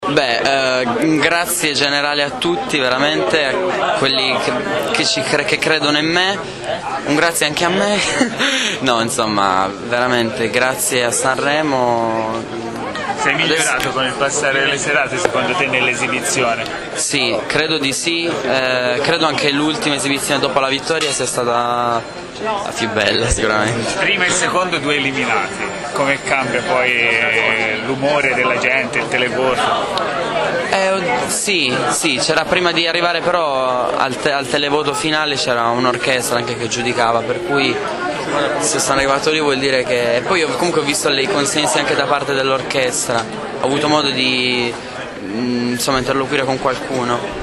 Dopo la proclamazione di Valerio Scanu come vincitore del 60� Festival di Sanremo, i primi 3 classificati sono saliti in sala stampa al Roof dell�Ariston, per la tradizionale conferenza.